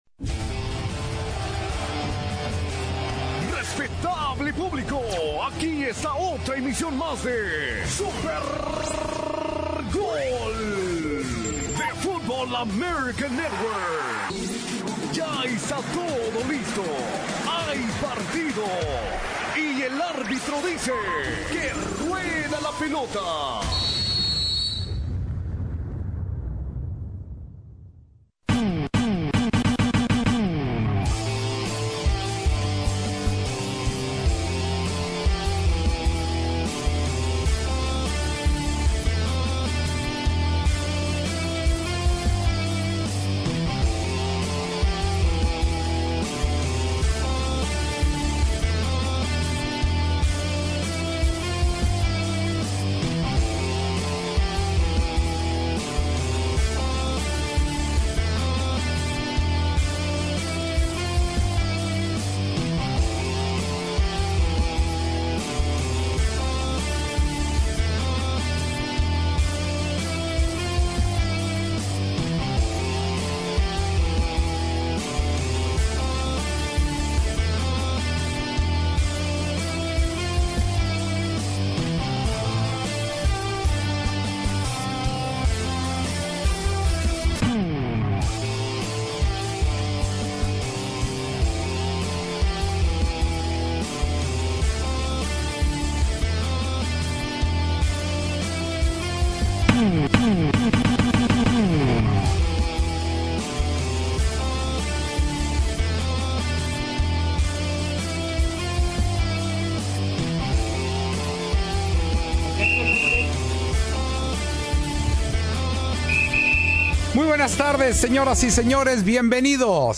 FALLECE EL LEGENDARIO FERNANDO EL TORO VALENZUELA. ANECDOTAS Y RECUERDOS DE SU HAZAÑA EN LA SERIE MUNDIAL DE 1981. ULTIMO INNING DE ESA SERIE MUNDIAL NARRADO POR MAGO SEPTIEN.